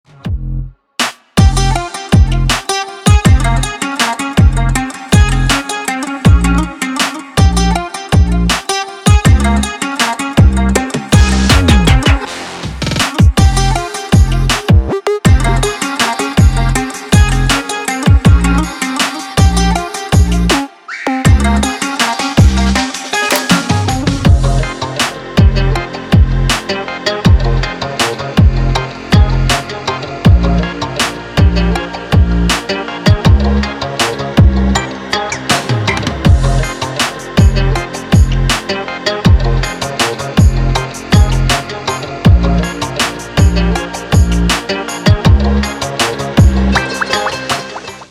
Громкие звонки, звучные рингтоны